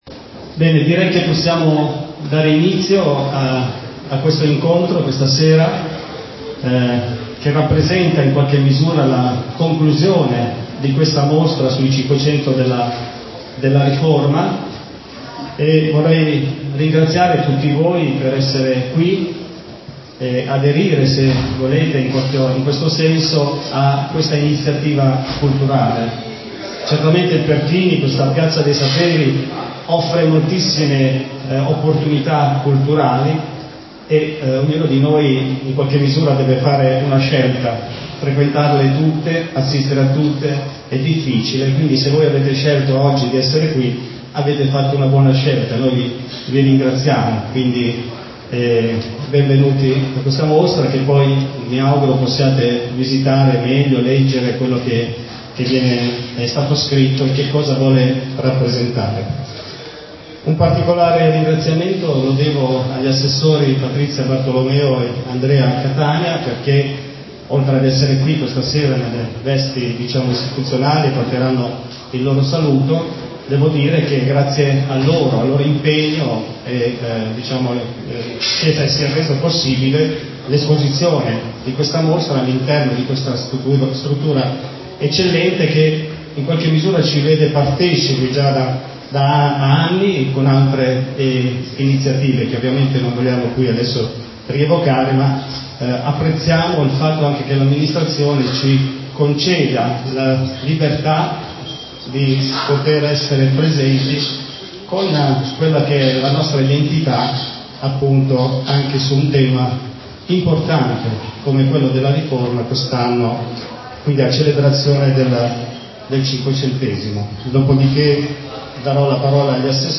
Lutero e l’attualità: quale rilevanza della Riforma? Una conferenza a Cinisello Balsamo (MI)